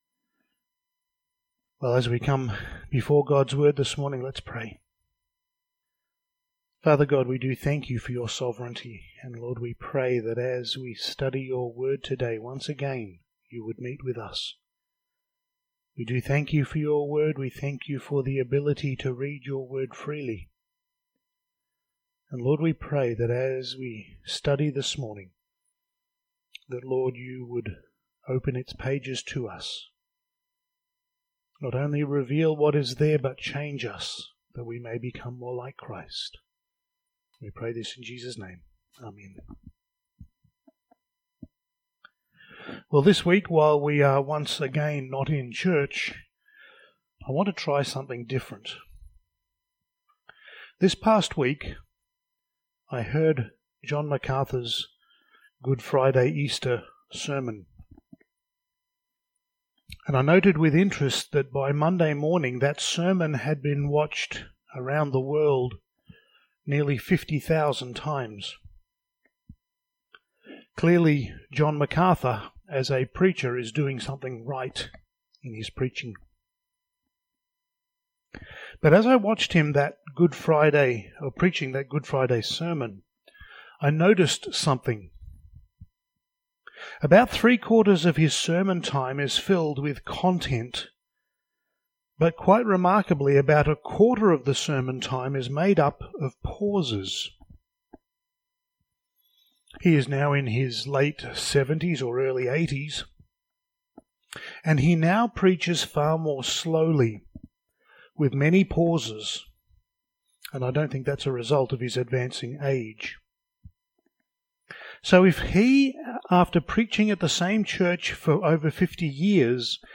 Passage: Acts 2:25-36 Service Type: Sunday Morning